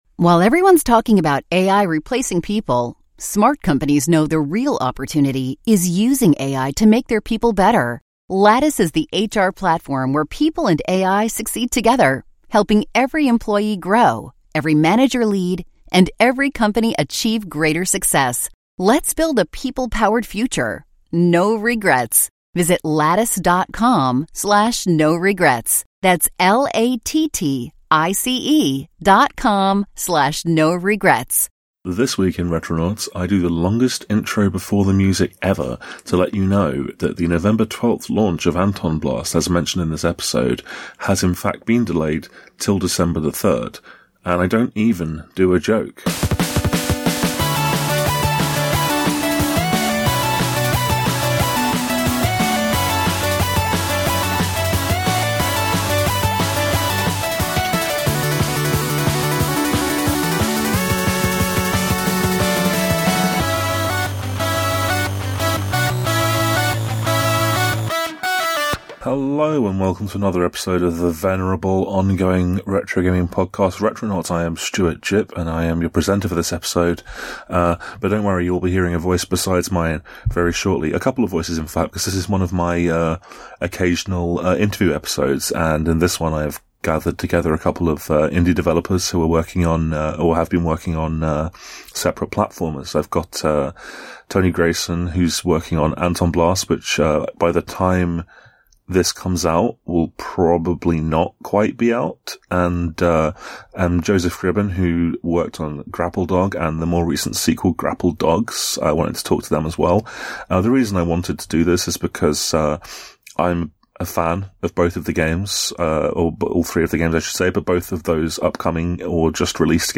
We hope this conversation helps both games find a wider audience!